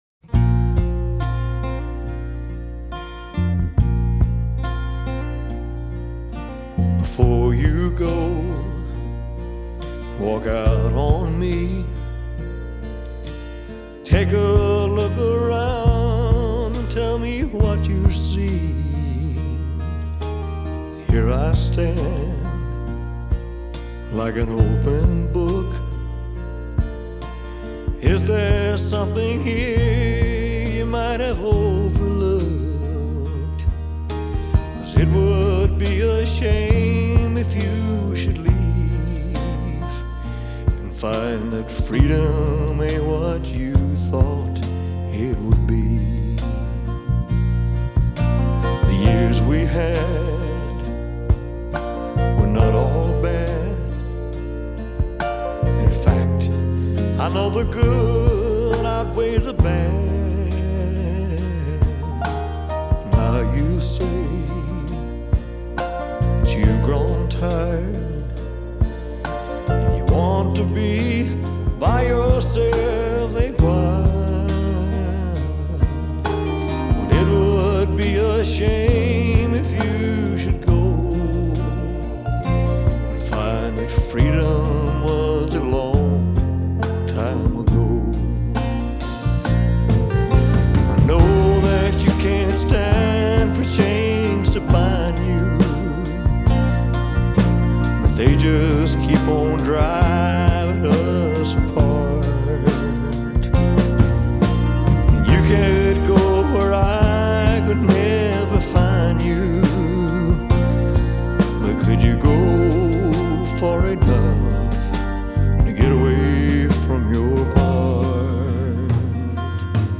Genre : Classic, Pop